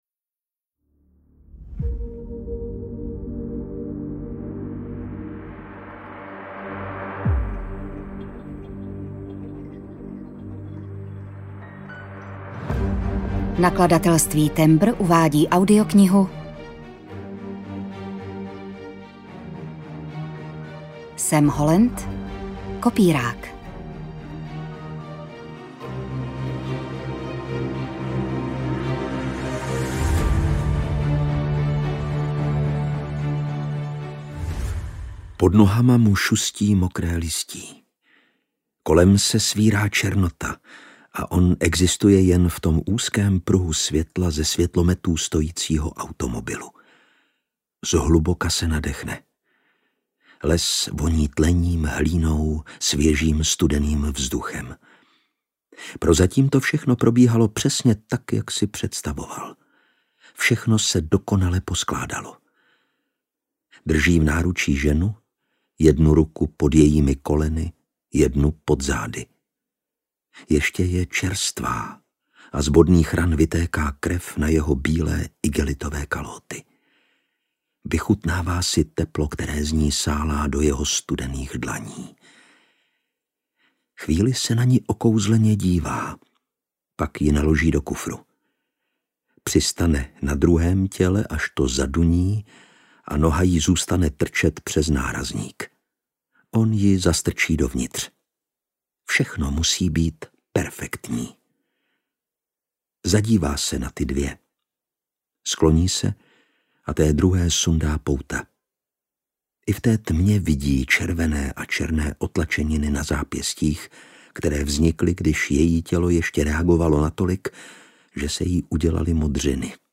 Audiokniha Kopírák, kterou napsal Sam Holland.
Ukázka z knihy